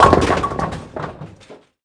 bowling03.mp3